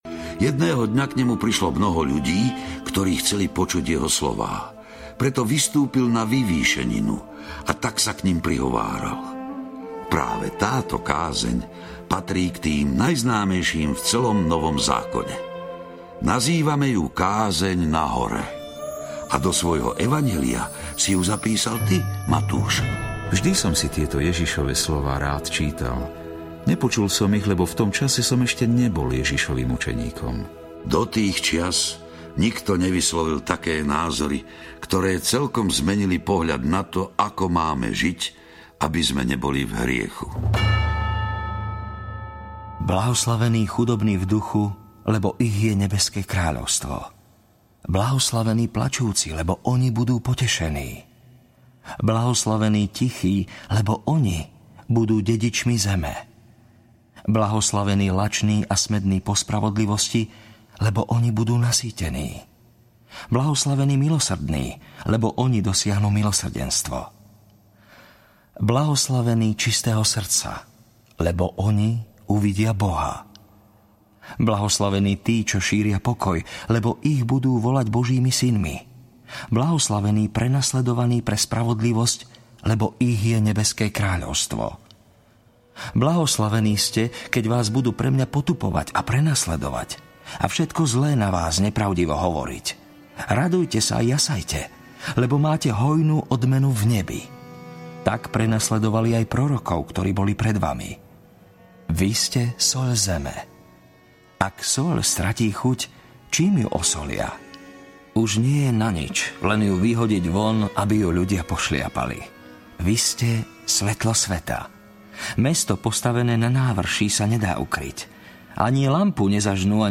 Biblia - Život Ježiša 1 audiokniha
Biblia - Život Ježiša 1 - dramatizované spracovanie Biblie podľa Nového zákona.